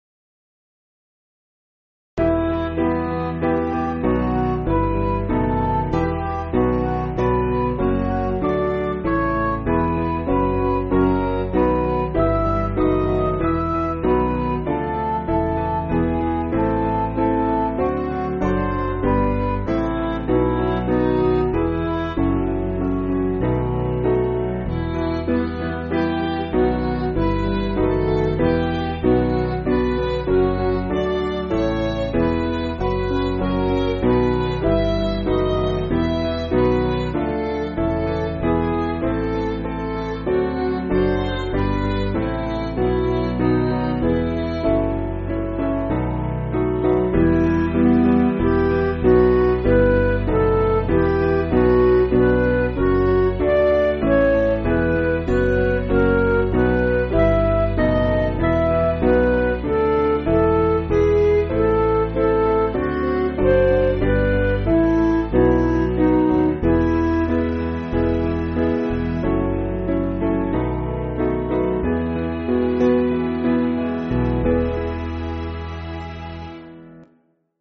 Piano & Instrumental
(CM)   3/Em